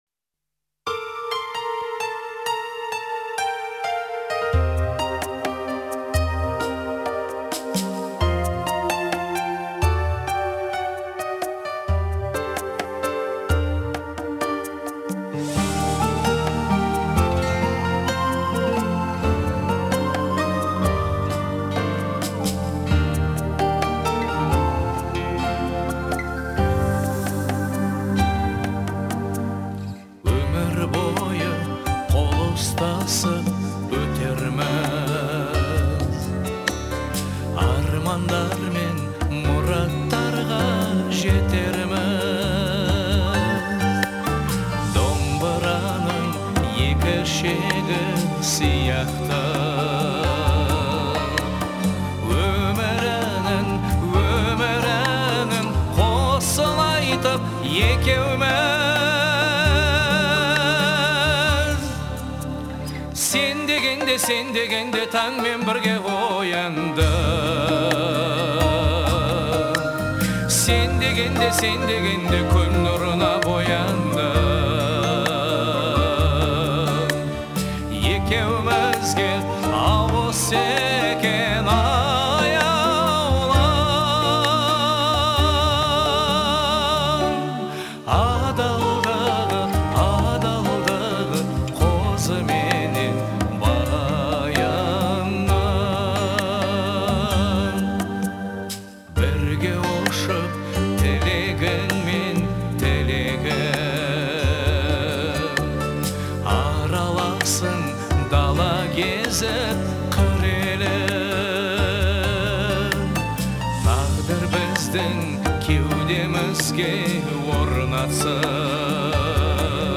обладая сильным голосом и харизмой